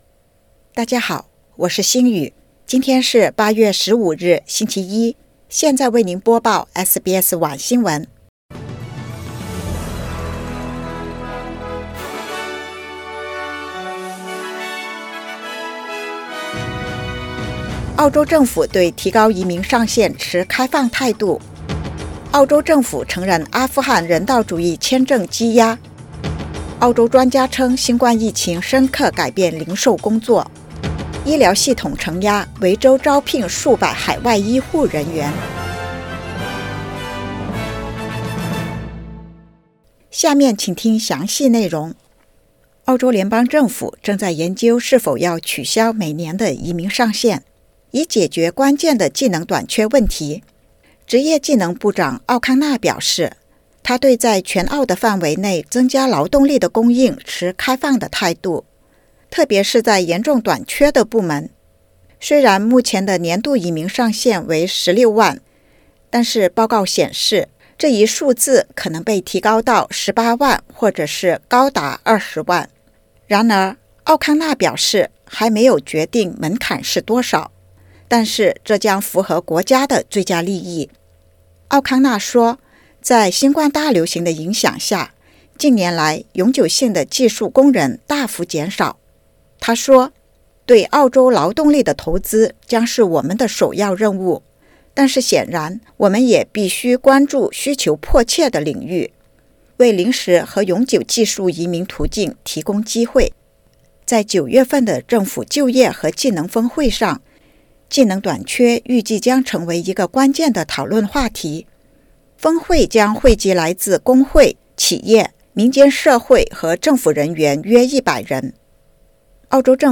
SBS晚新闻（2022年8月15日）